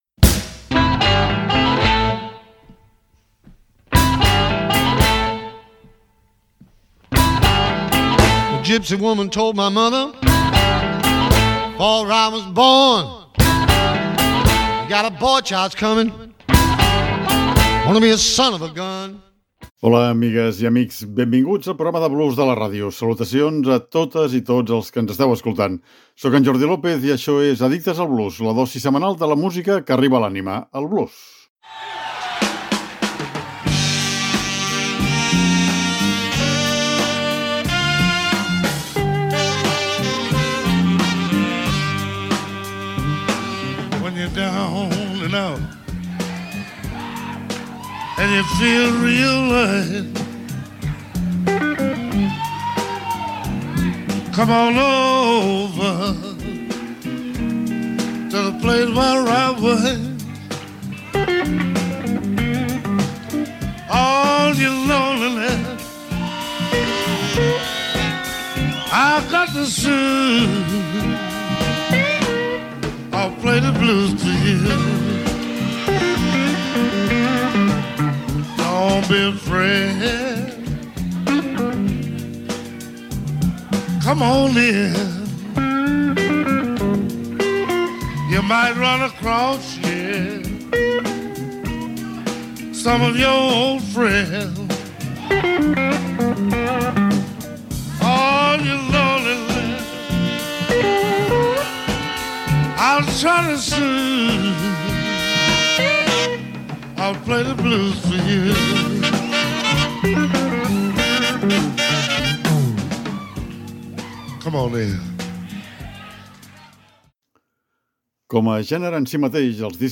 Avui us proposem una nova llista d’àlbums de blues en directe.